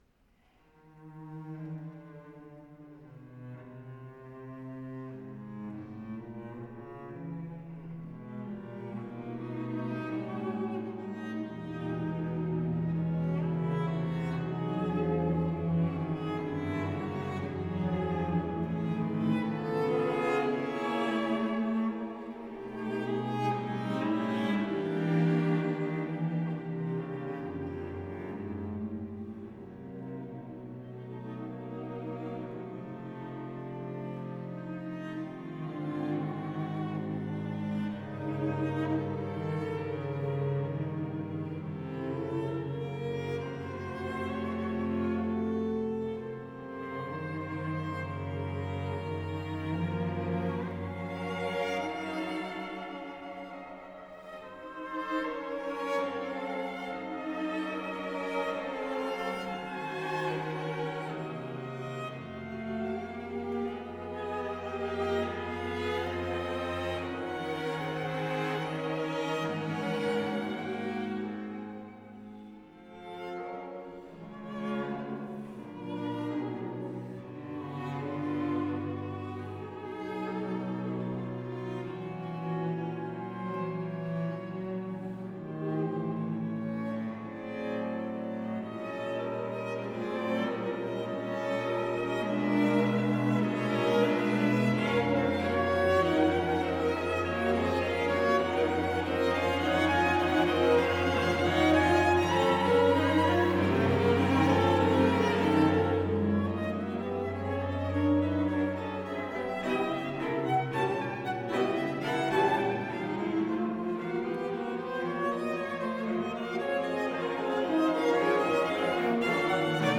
Chiesa di San Rocco – Asiago, 13 agosto 2019
Genere: Classical.